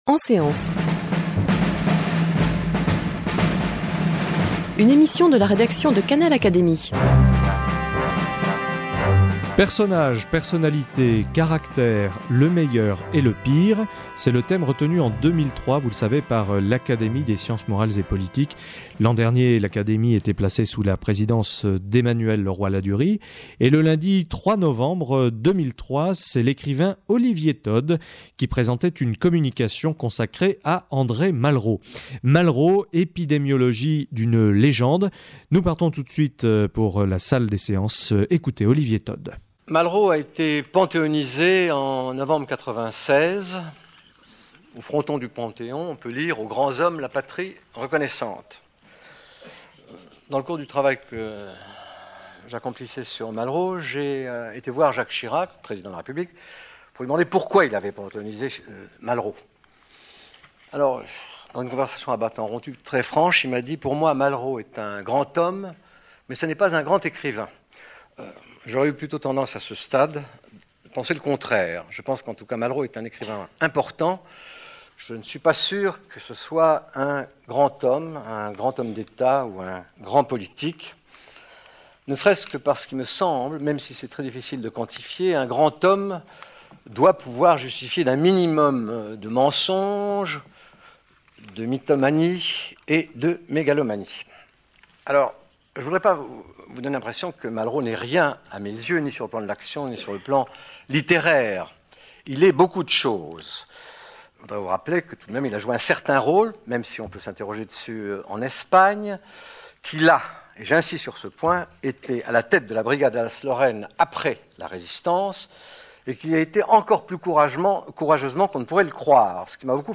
Communication de l’écrivain Olivier Todd prononcée en séance publique devant l’Académie des sciences morales et politiques- le lundi 3 novembre 2003.